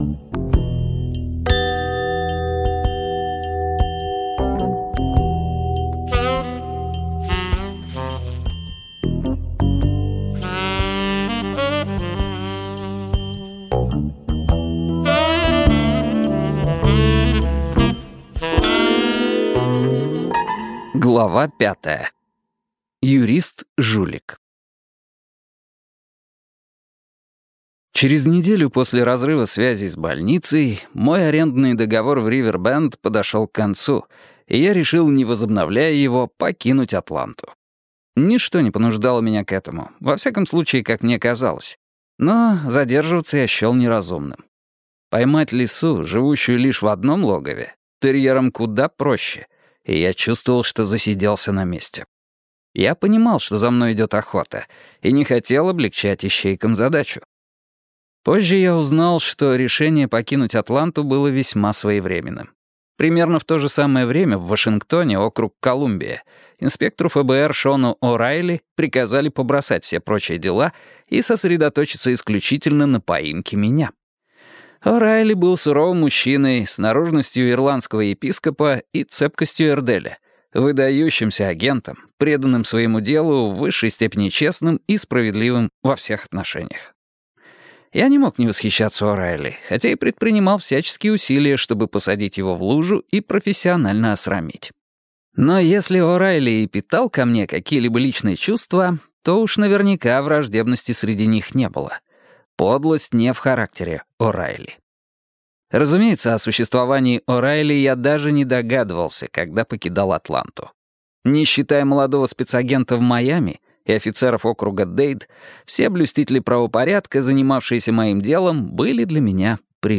Аудиокнига Поймай меня, если сможешь. Реальная история самого неуловимого мошенника за всю историю преступлений | Библиотека аудиокниг